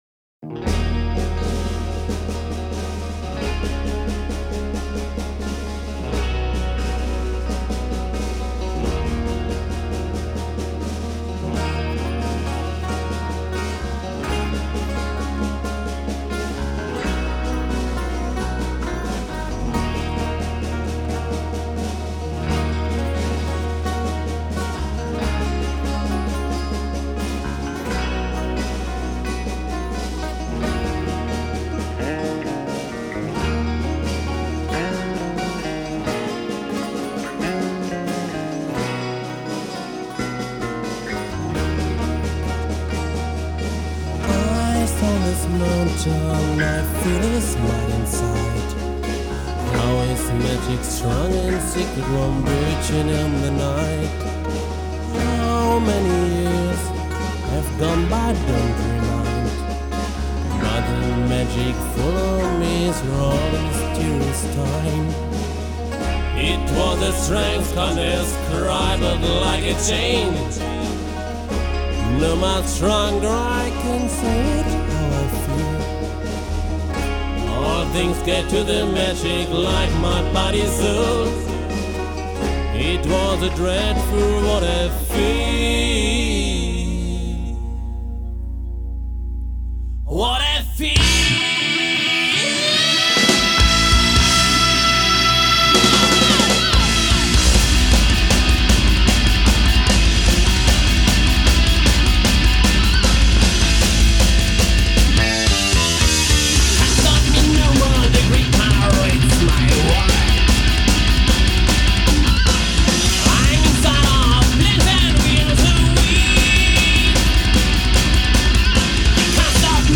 ♬ Dark Atmospheric Rock ♬